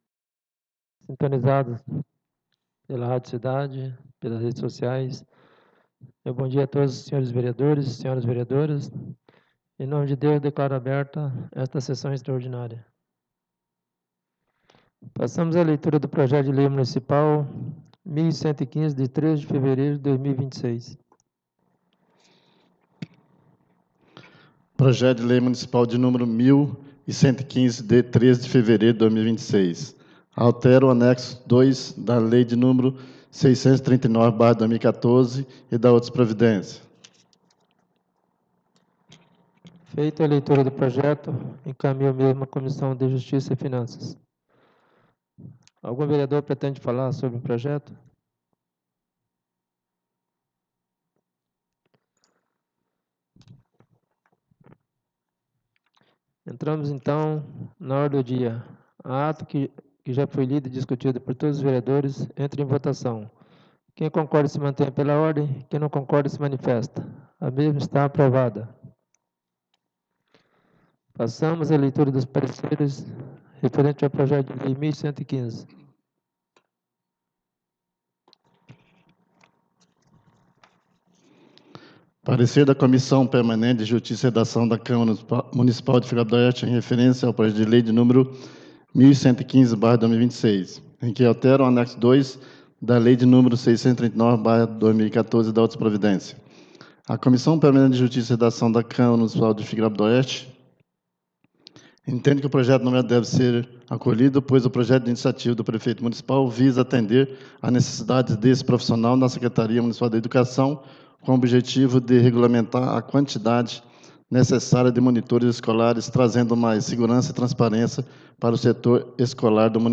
4° SESSÃO EXTRAORDINÁRIA DE 18 DE FEVEREIRO DE 2026